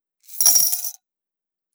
Base game sfx done
Saving Coins 03.wav